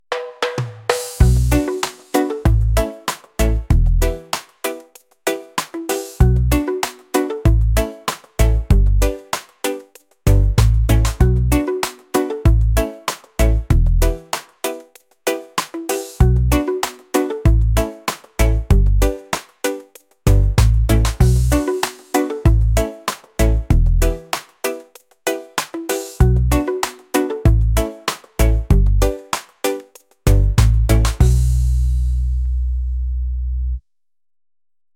reggae | lofi & chill beats | ambient